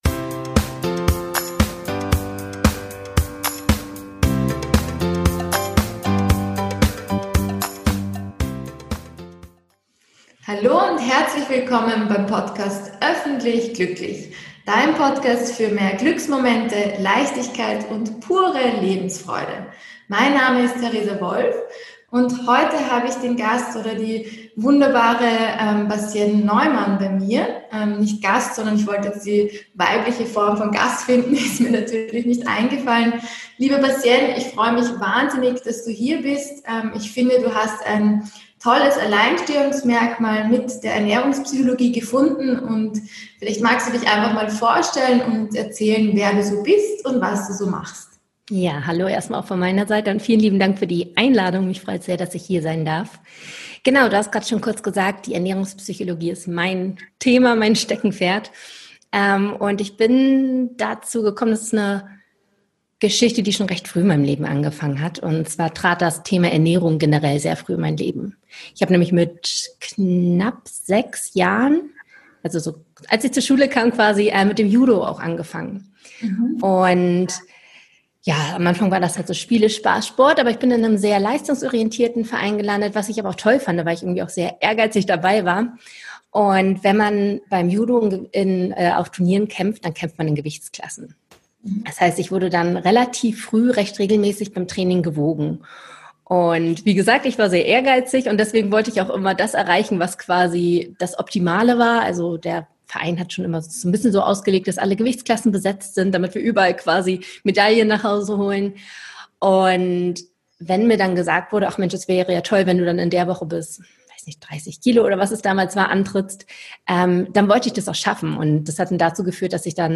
#30 [Interview] Warum Ernährung und Psychologie unbedingt zusammengehören ~ Öffentlich Glücklich Podcast